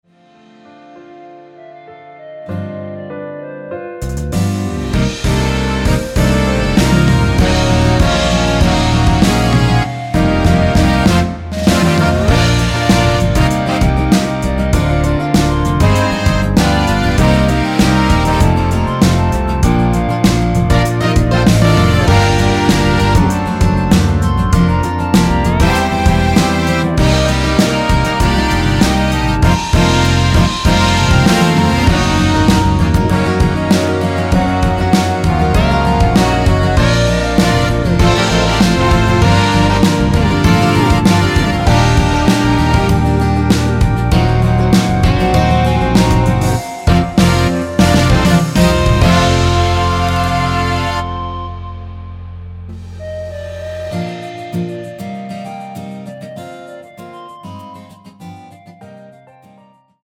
미리듣기는 “후 살며시 네가 불어와” 부터 시작됩니다.
원키에서(-2)내린 멜로디 포함된(1절+후렴)으로 진행되는 MR입니다.
Bb
앞부분30초, 뒷부분30초씩 편집해서 올려 드리고 있습니다.
중간에 음이 끈어지고 다시 나오는 이유는